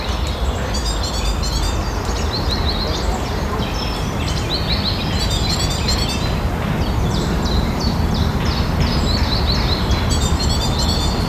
oiseau inconnu, ni vu ni connu
Eventuellement il parvient � identifier le Pouillot v�loce en arri�re plan. J'ai essay� de r�duire le BDF au mieux mais le SNR n'est pas au top. Observ� tout � l'heure en for�t, IDF ouest.